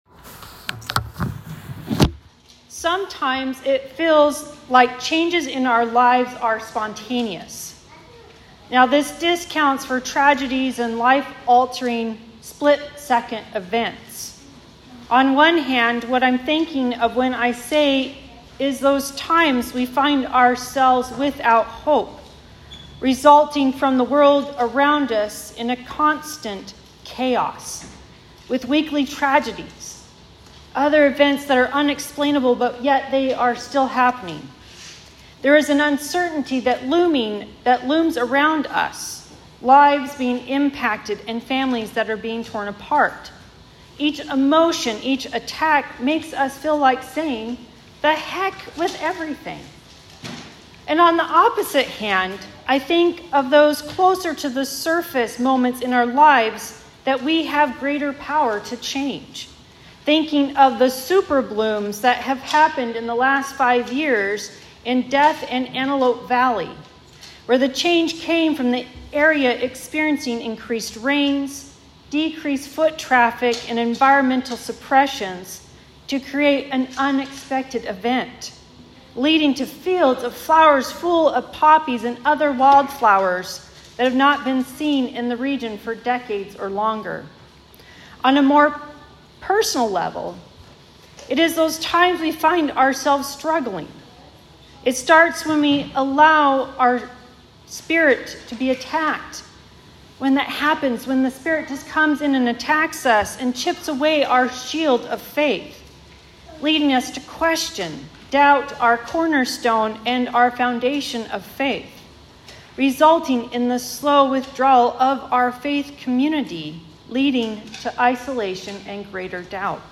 Sermons | Fir-Conway Lutheran Church